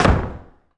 door_close_slam_02.wav